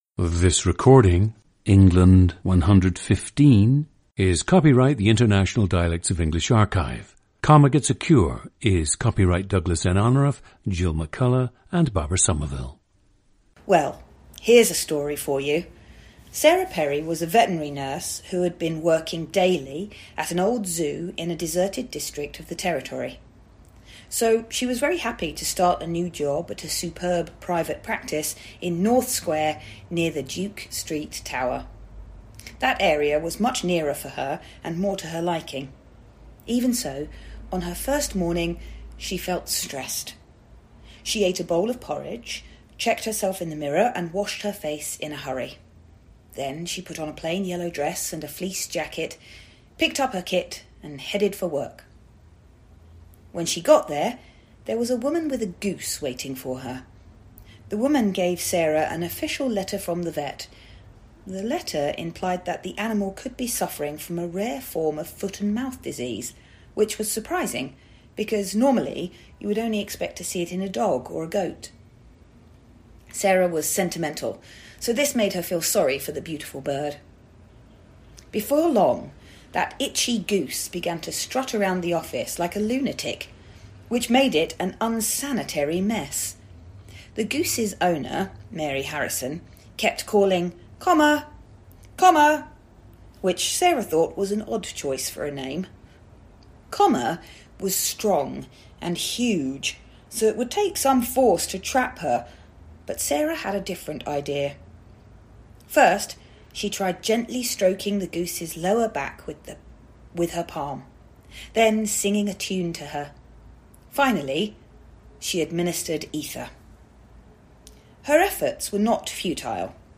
GENDER: female
There are no significant influences on the subject’s speech pattern.
When she switches into conversation, however, one can hear strong characteristics of a working-class and/or “Estuary” dialect. She glottalizes many medial and final Ts, drops the occasional H (as in “hospital”), and bends diphthongs such as ei, ai, and ou in the manner typical of London and other Southeast dialects. This sample is an interesting example of code-switching: When she is reading, she adopts a more formal sound, while her extemporaneous speech is more relaxed and her accent thereby more vivid.
The recordings average four minutes in length and feature both the reading of one of two standard passages, and some unscripted speech.